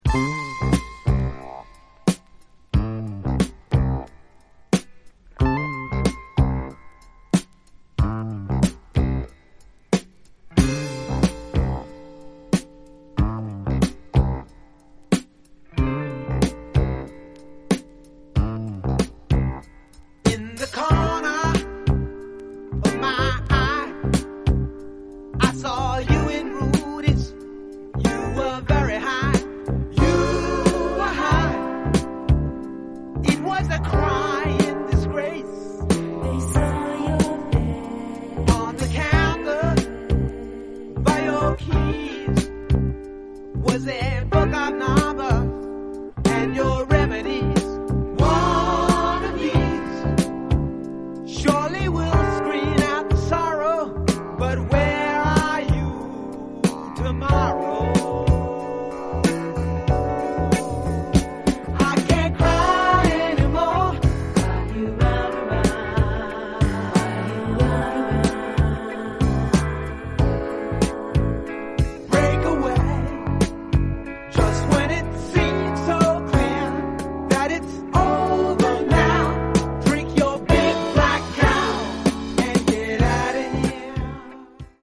jazz rock band